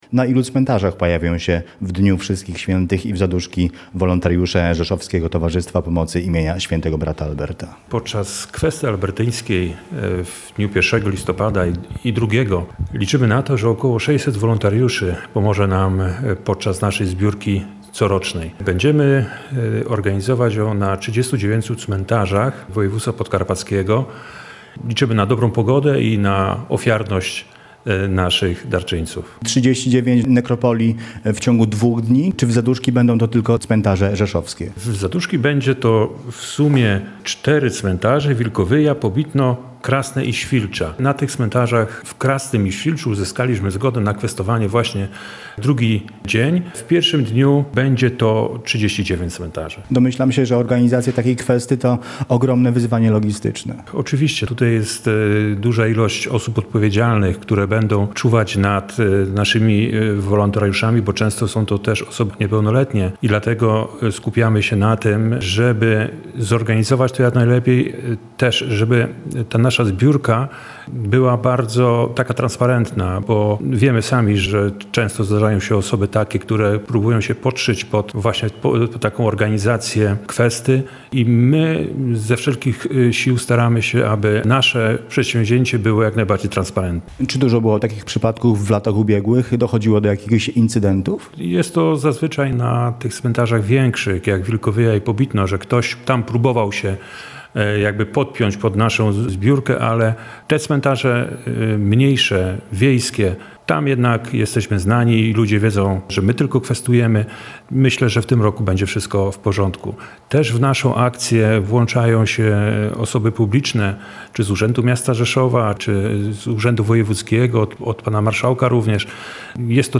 Szczegóły w rozmowie